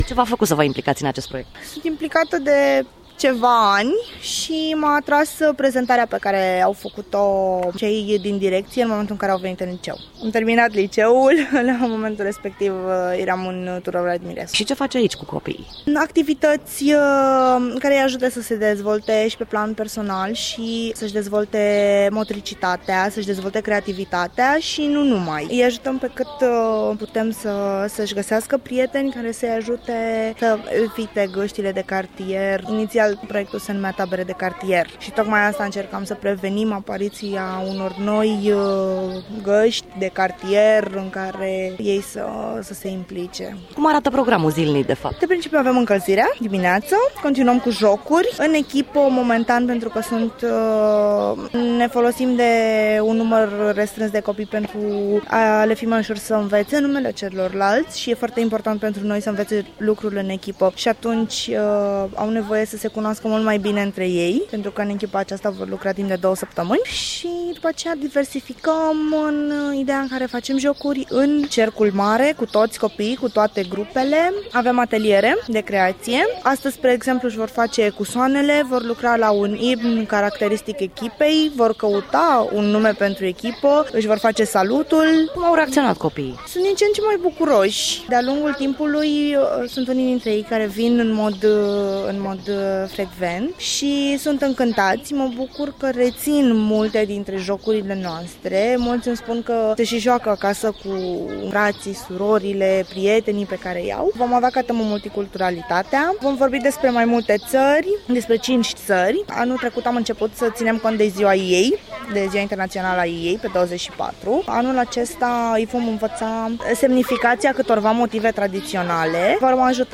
Anul acesta tabara va avea un itinerar inedit care va cuprinde cinci tari: America, Romania, Grecia, Japonia si Turcia cu jocuri si concursuri specifice acestor tari spune unul dintre voluntarii proiectului: